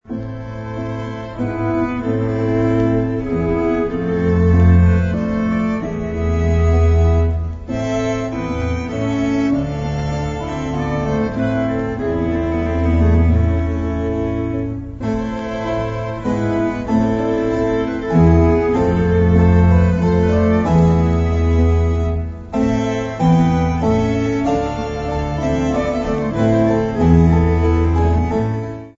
Δείγματα από τη μουσική της παράστασης